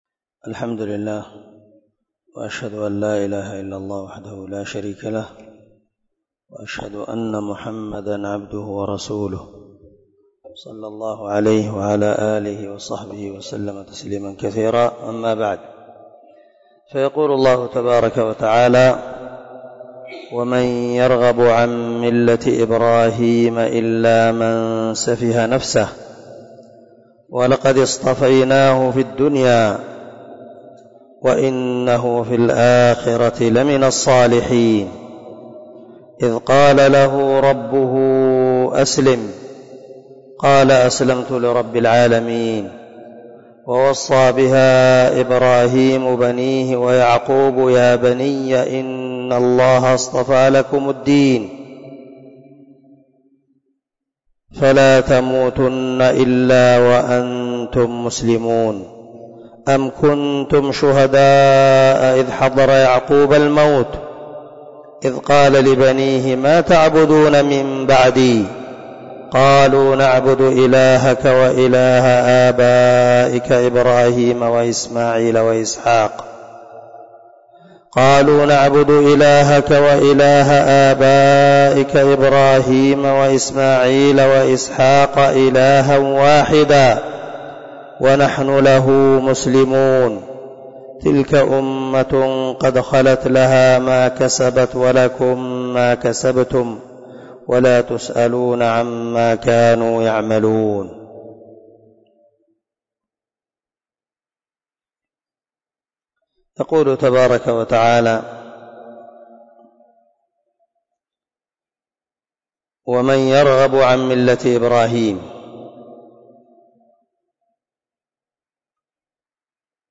054الدرس 44 تفسير آية ( 130 – 134 ) من سورة البقرة من تفسير القران الكريم مع قراءة لتفسير السعدي